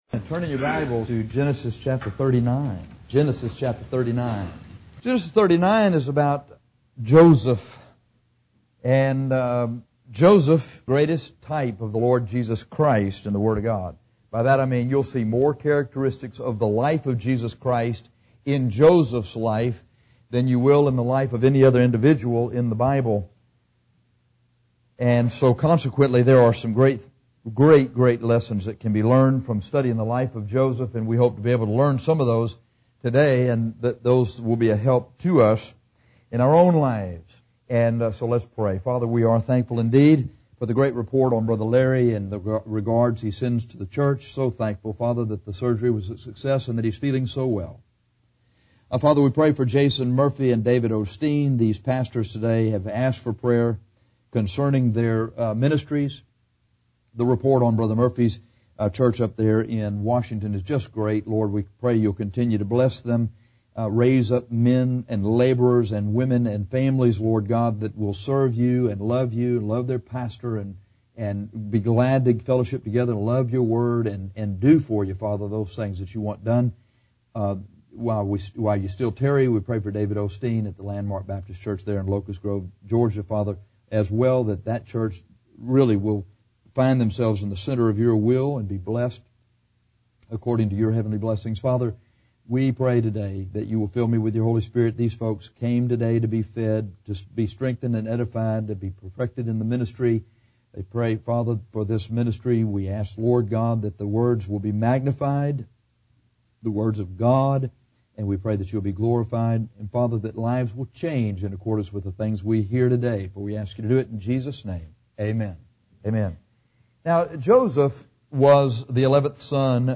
This sermon is about the success of Joseph. How did Joseph succeed?